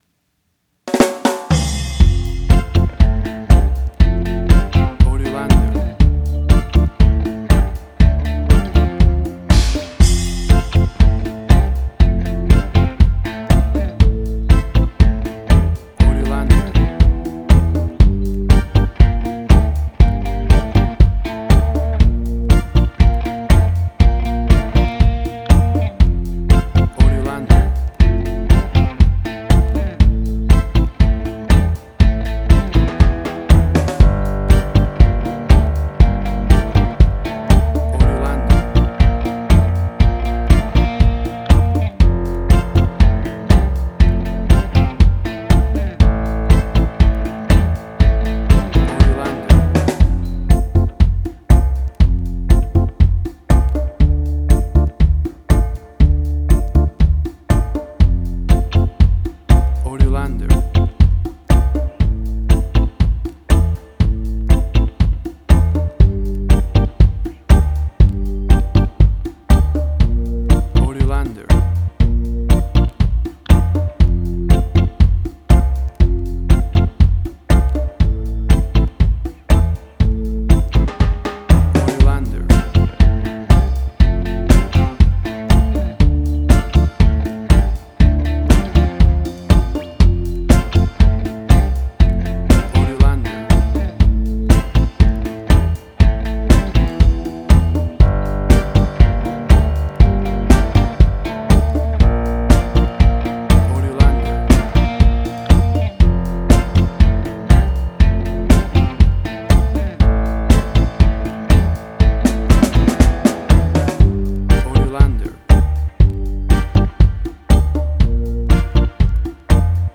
Reggae caribbean Dub Roots
Tempo (BPM): 120